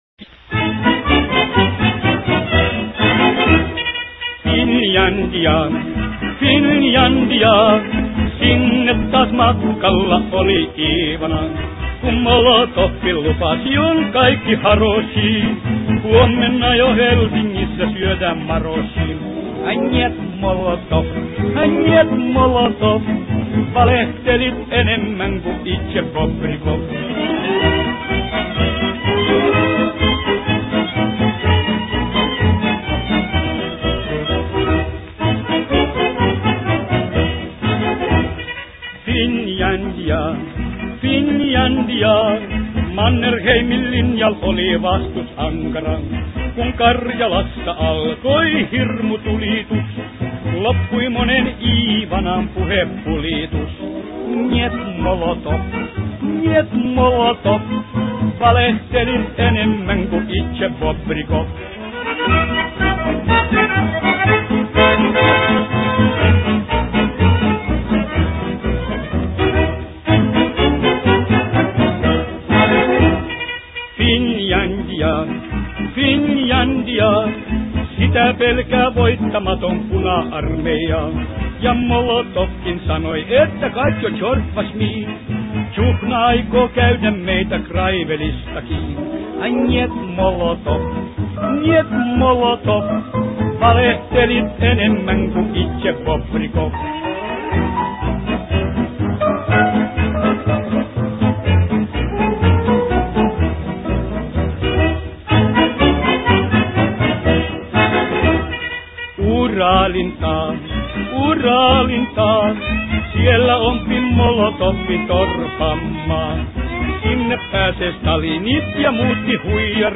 он начинает петь грустную песню.